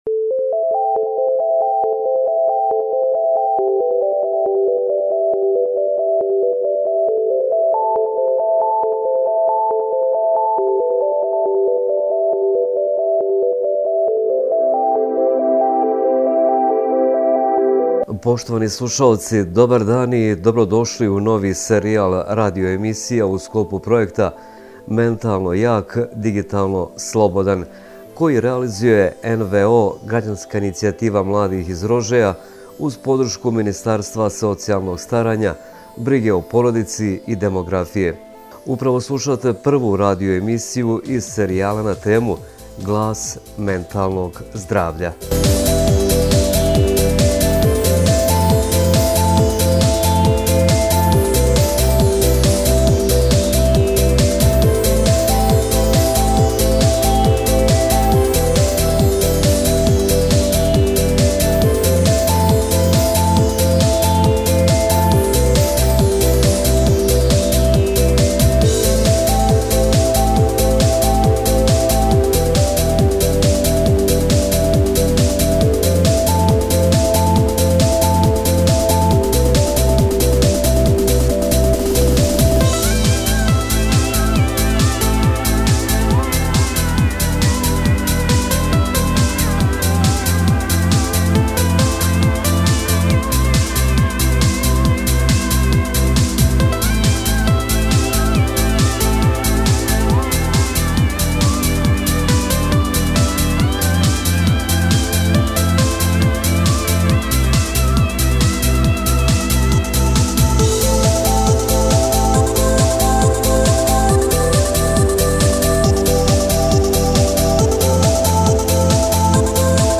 Radio emisija: Glas mentalnog zdravlja